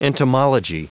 Prononciation du mot entomology en anglais (fichier audio)
Prononciation du mot : entomology